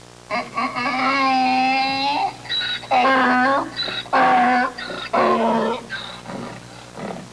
Donkey Sound Effect
Download a high-quality donkey sound effect.
donkey.mp3